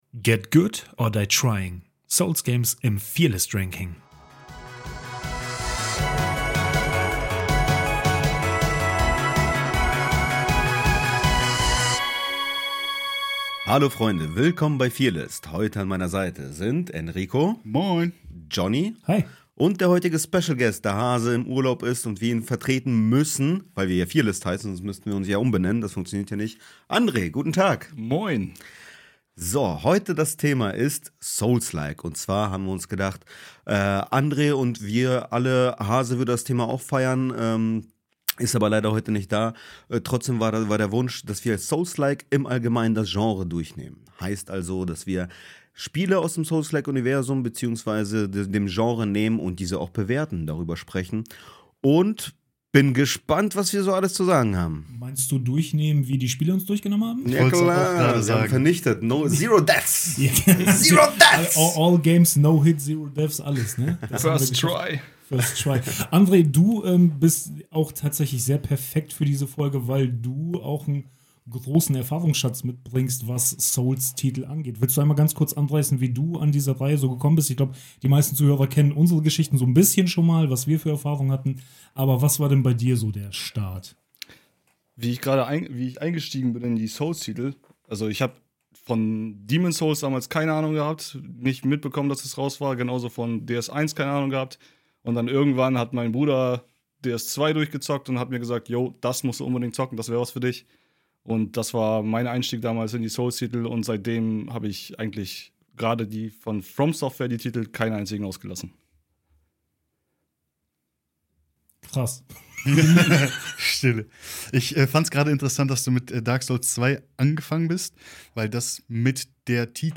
*Leider gab es technische Probleme beim Aufnehmen, weshalb die Qualität der Folge nicht so hoch ist wie sonst.